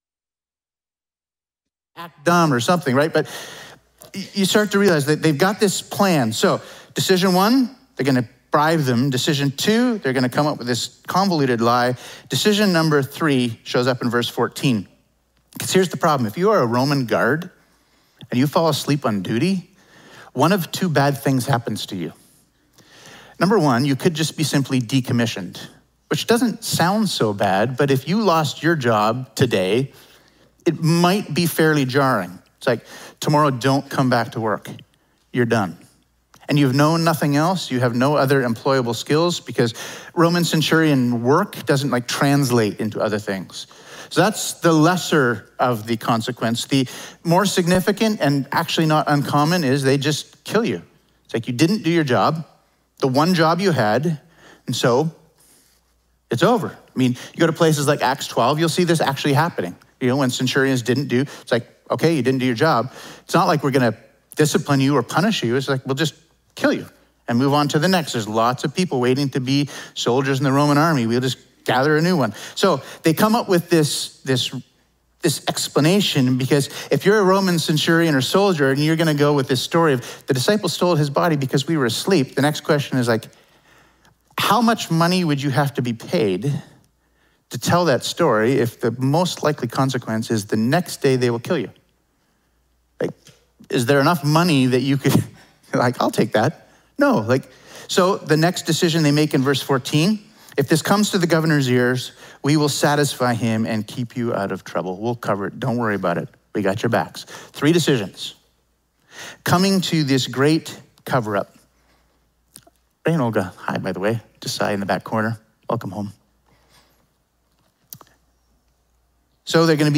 Sermons | Emmanuel Baptist Church
Apologies, due to technical difficulties, we lost audio from around 41:01 until 56:19, just over 15 minutes of the sermon.